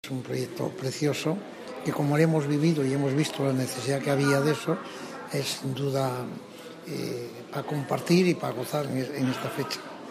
Por su parte, el padre Ángel, presidente de la Fundación Mensajeros de la Paz que ha intermediado en esta iniciativa y que mantiene una continuada línea de cooperación con aquellos territorios, se congratulaba también de